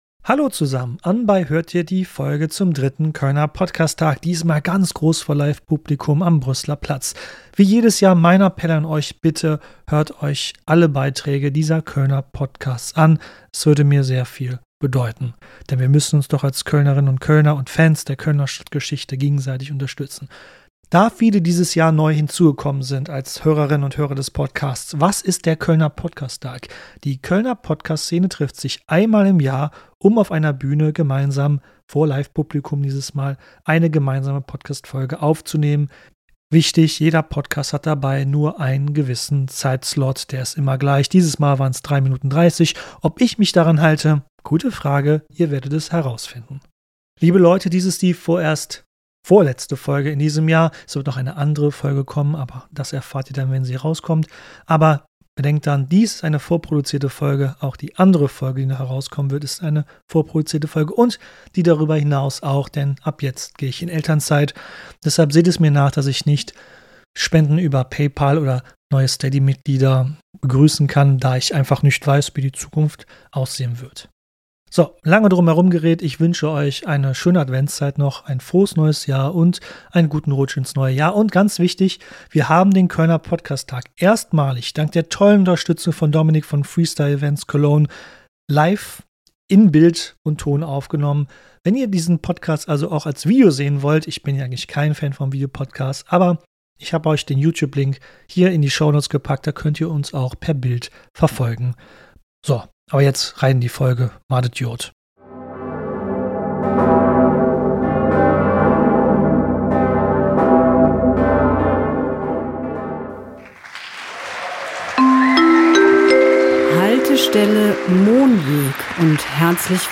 Wieder einmal haben die kölschen Podcaster eine gemeinsame Folge produziert. Alle sind mit Witz und Leidenschaft dabei. Aufgenommen live und voller Atmosphäre macht dieser Podcast hörbar, warum Köln so ist, wie es ist: vielfältig, widersprüchlich, lebendig.